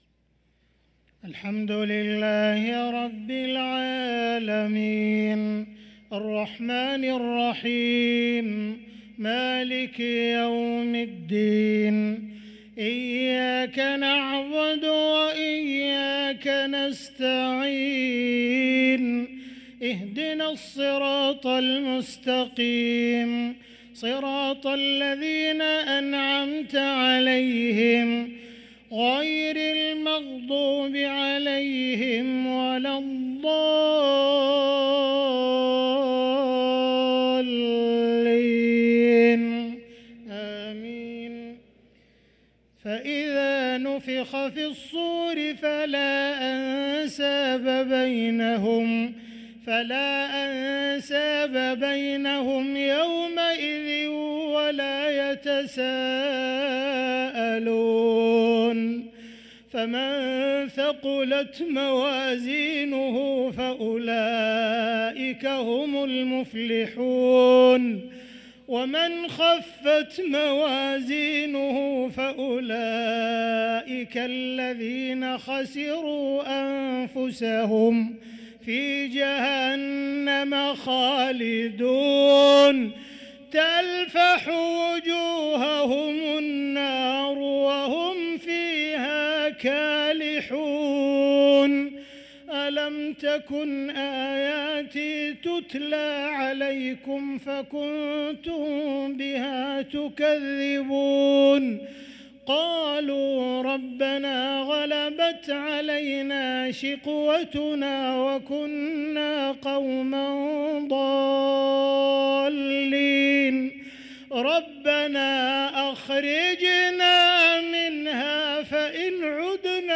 صلاة العشاء للقارئ عبدالرحمن السديس 20 جمادي الأول 1445 هـ
تِلَاوَات الْحَرَمَيْن .